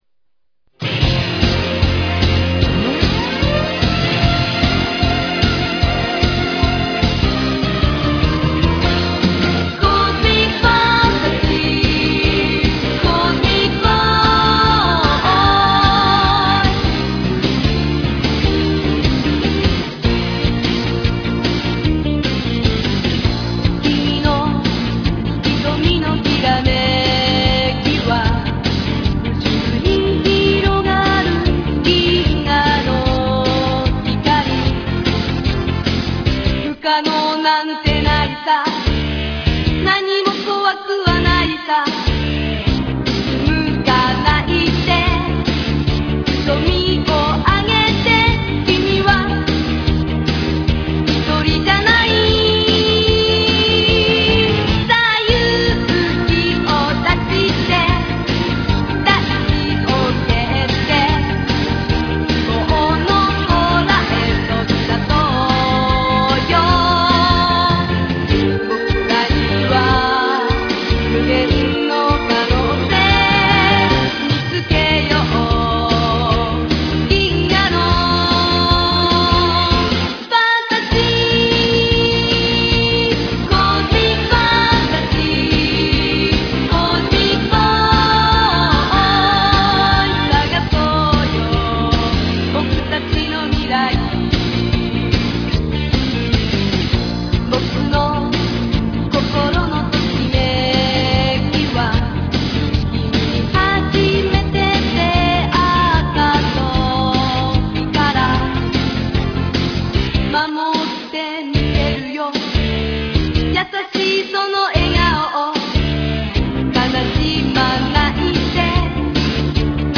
followed by the official theme song
reproduced here as a 458KB mono RealAudio recording